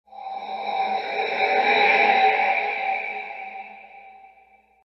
Elden Ring Spirit Ashes Summoned Sound Button | Sound Effect Pro
Instant meme sound effect perfect for videos, streams, and sharing with friends.